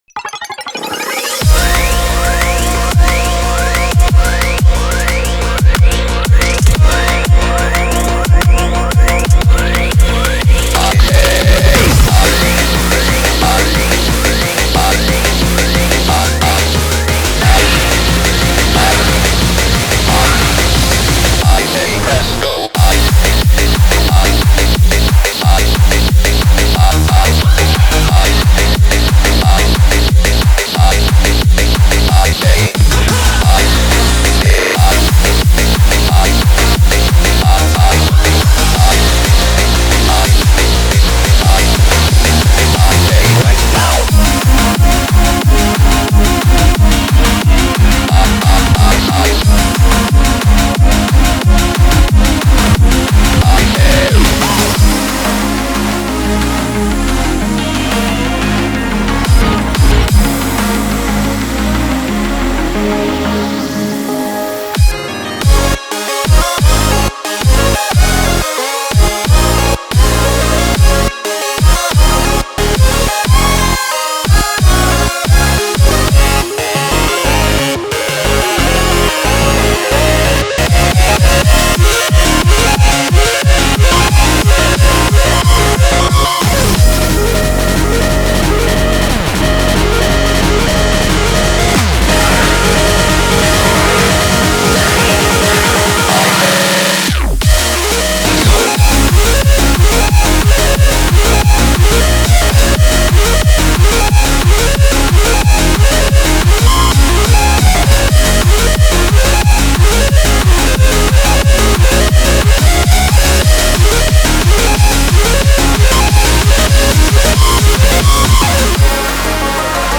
BPM180
Audio QualityPerfect (High Quality)
Comments[UK HARDCORE]